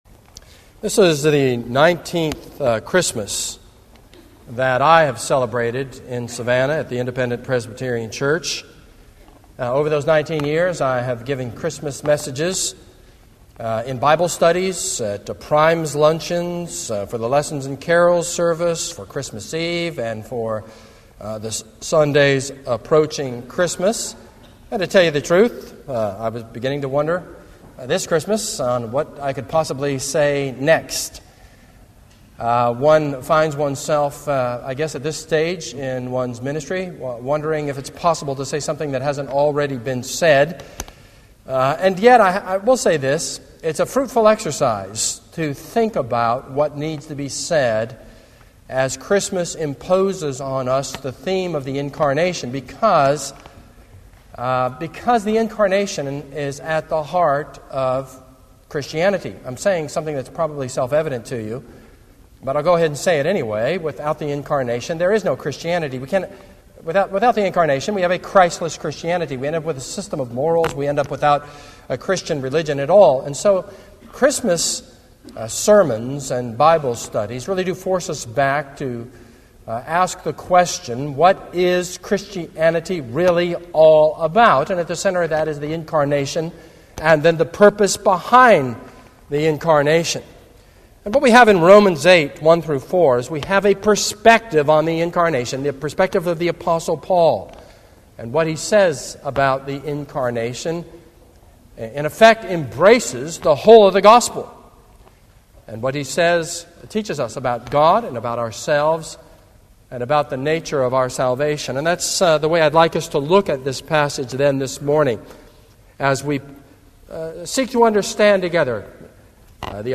This is a sermon on 2 Thessalonians 1.